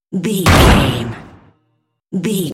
Cinematic stab hit trailer
Sound Effects
Atonal
heavy
intense
dark
aggressive
hits